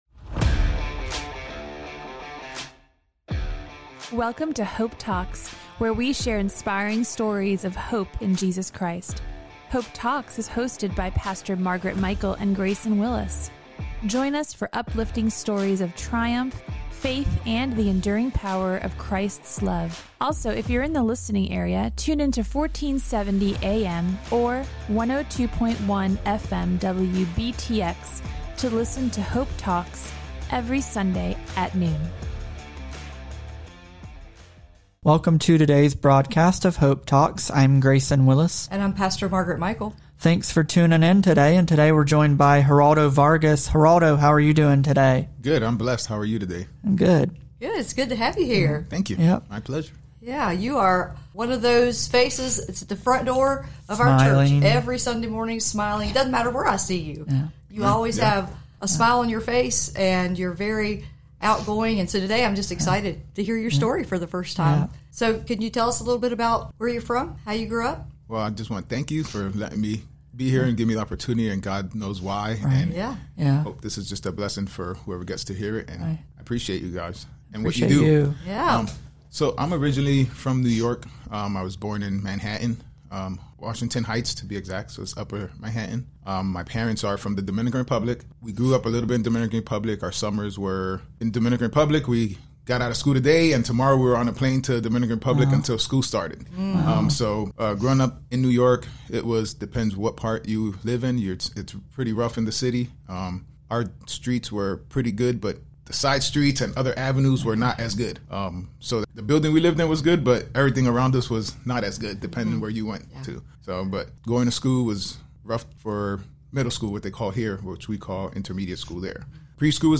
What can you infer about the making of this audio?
Hope Talks airs every Sunday at noon on 1470 AM and 102.1 FM WBTX.